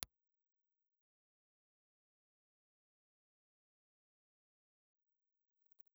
Impluse Response file of the BSR ribbon microphone. The transfomer in this microphone has been replaced.
BSR_new_Xformer.wav
Note that the sound files and sweeps provided for this model are from restored microphones that have been fitted with new magnets and ribbons.